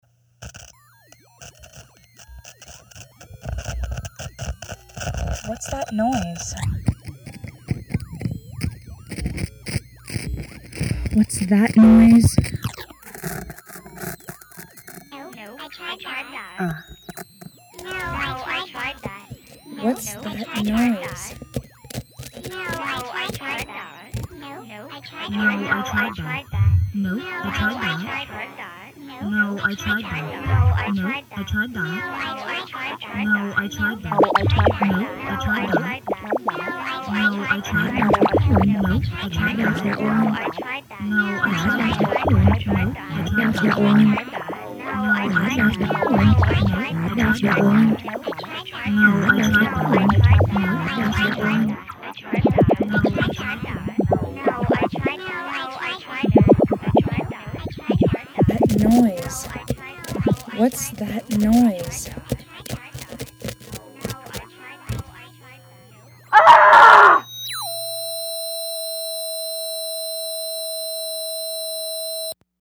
I cried – the only time I ever cried in studio – and finally decided to record my frustrations and turn that into a piece.